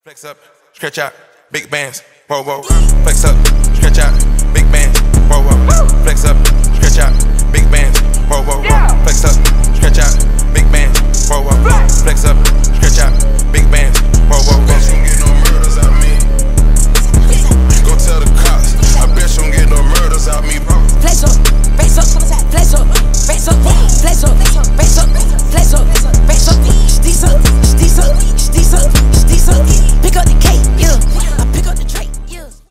Рэп и Хип Хоп
громкие